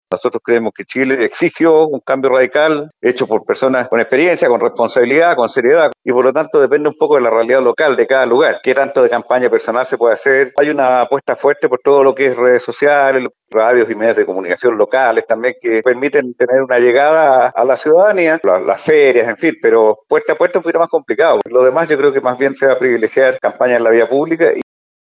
En este sentido, el presidente del Partido Radical, Carlos Maldonado, reconoció que se debió hacer un cambio en los métodos tradicionales de campaña, debido a la pandemia y anticipó que su fuerte serán las redes sociales, seguido por las tradicionales propagandas en medios de comunicación, además de visitar algunos territorios.